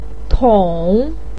tou3.mp3